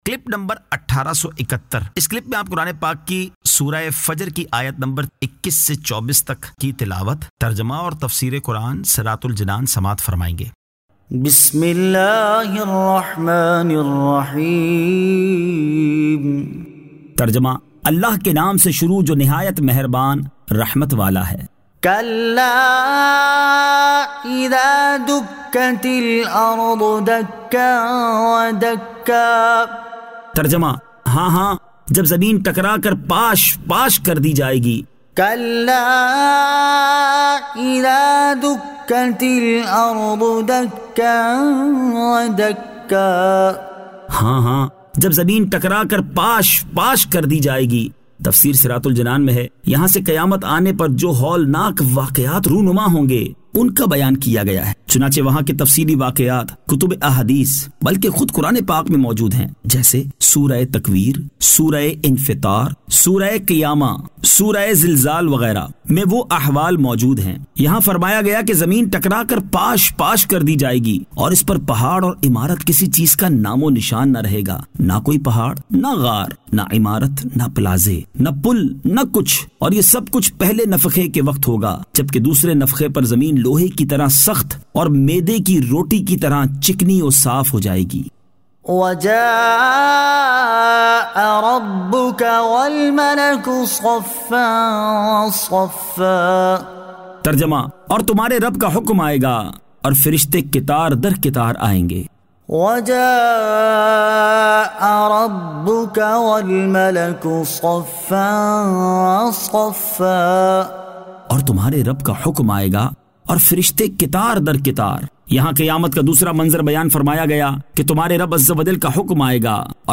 Surah Al-Fajr 21 To 24 Tilawat , Tarjama , Tafseer